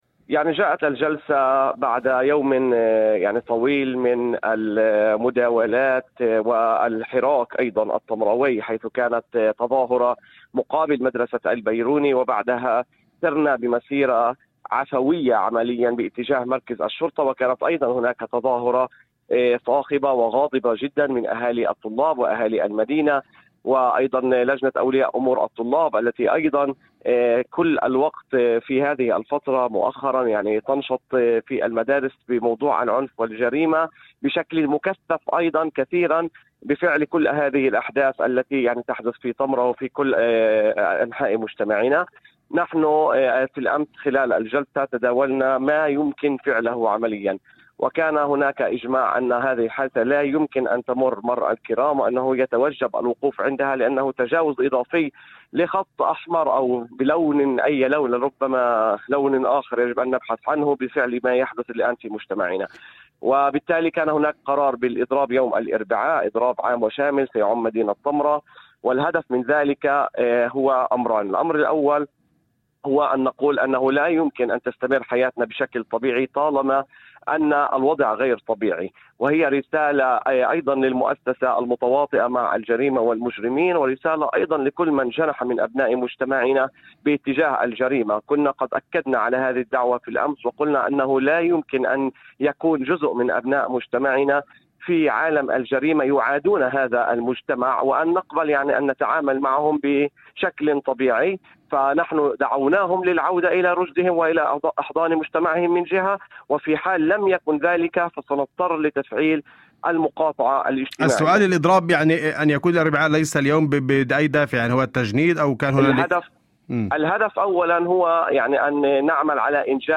في مداخلة هاتفية ضمن برنامج "أول خبر" على إذاعة الشمس